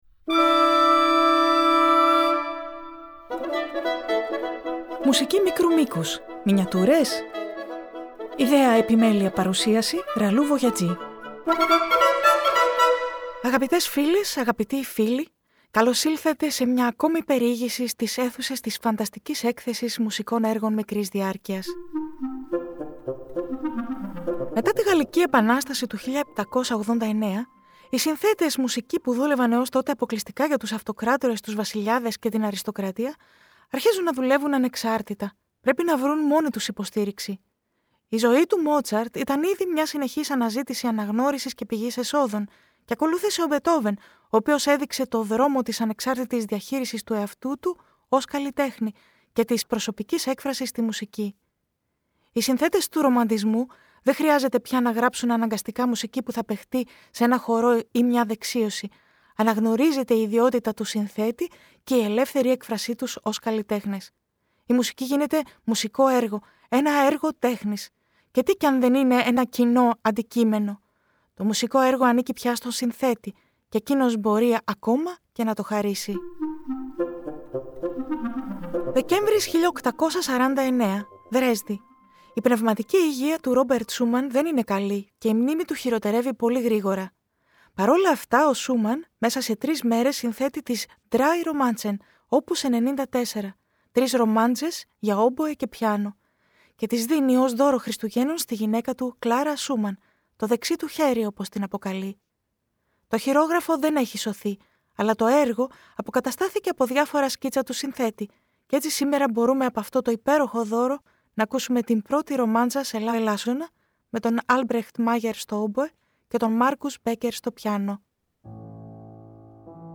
Η ‘’Μουσική μικρού μήκους-Μινιατούρες;‘’ στις καθημερινές μικροσκοπικές και εβδομαδιαίες θεματικές εκπομπές προσκαλεί σε περιηγήσεις σε μια φανταστική έκθεση- σε μια ‘’ιδιωτική συλλογή’’ -μουσικών έργων ‘’μικρού μήκους’’ από όλες τις εποχές και τα είδη της αποκαλούμενης δυτικής ευρωπαϊκής λόγιας και συγγενών ειδών: